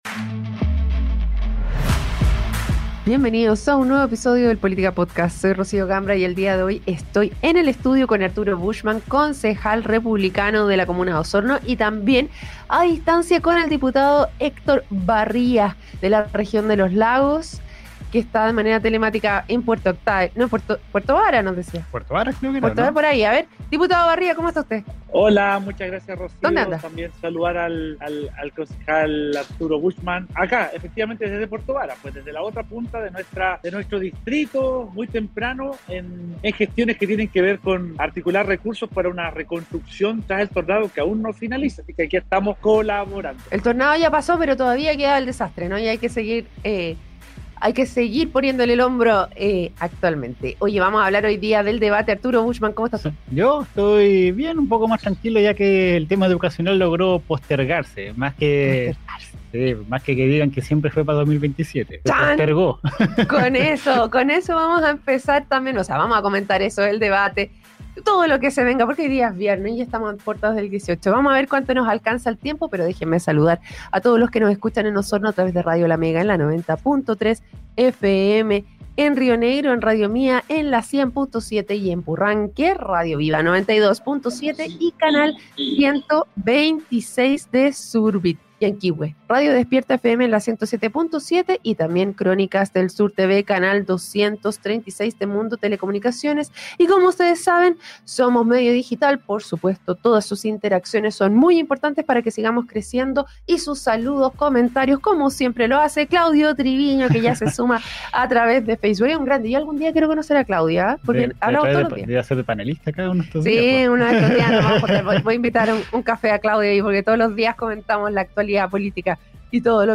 Diputado y Concejal critican "improvisación" y "desorden" en gestión municipal de Osorno
entrevista